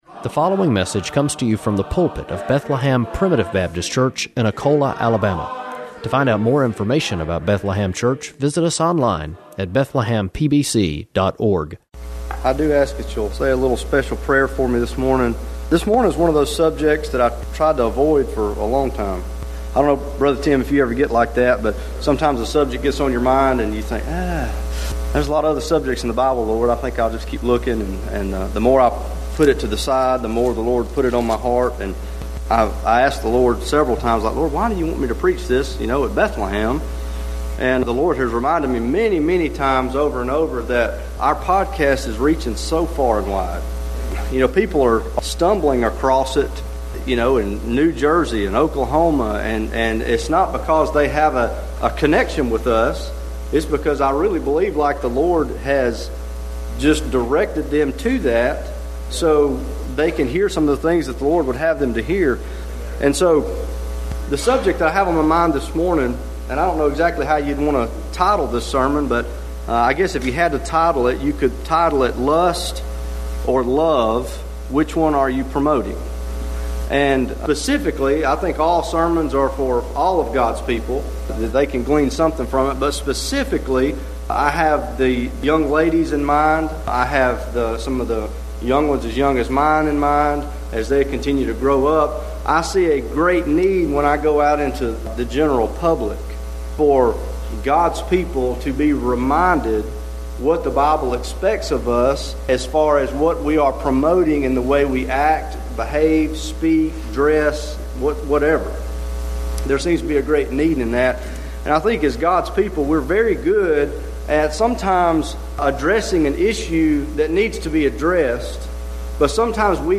Bethlehem Primitive Baptist Church